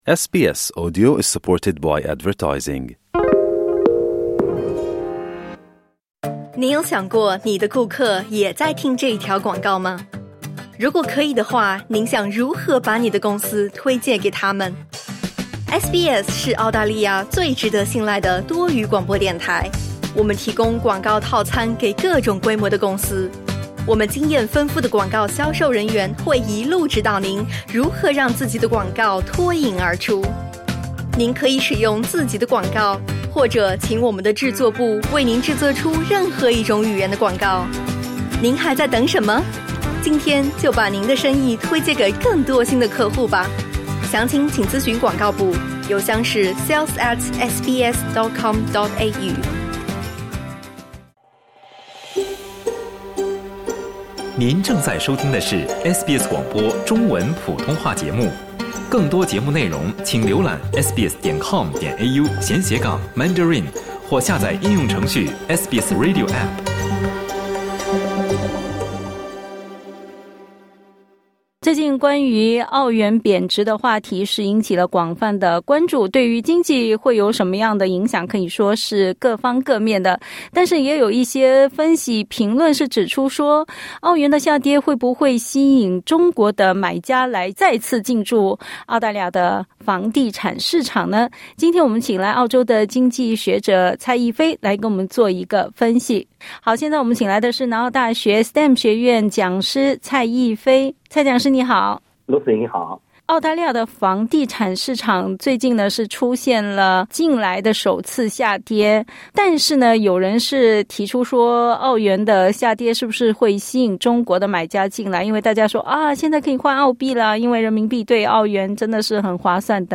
他指出，房地产投资是一种长期的行为，受到多方面因素的制约和影响。 （点击音频收听详细采访）